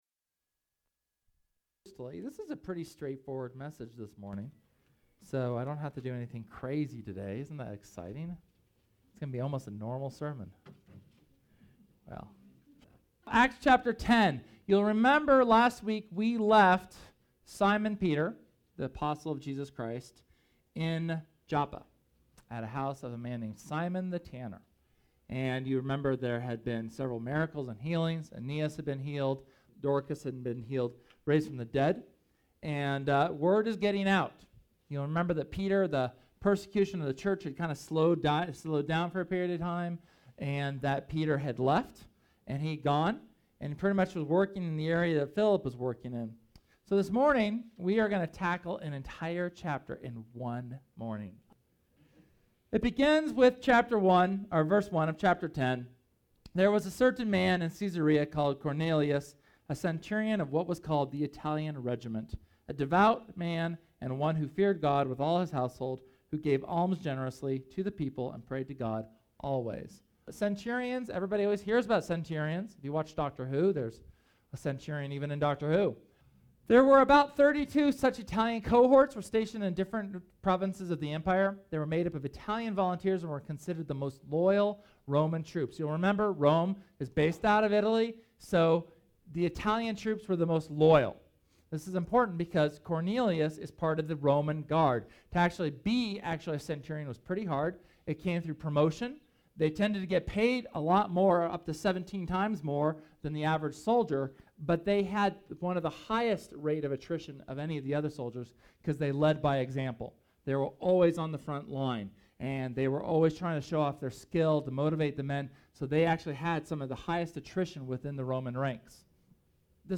SERMON: The Wall of Separation